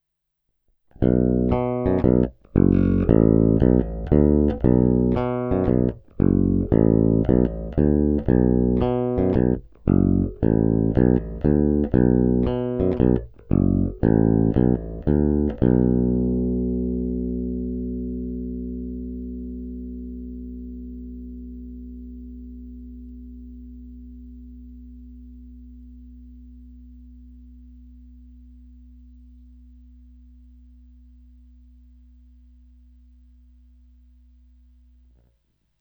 Baskytara má dostatek středů umožňujících jí se prosadit v kapele a zároveň tmelit zvuk.
Není-li uvedeno jinak, následující nahrávky jsou provedeny rovnou do zvukové karty, jen normalizovány, jinak ponechány bez úprav.
Snímač u kobylky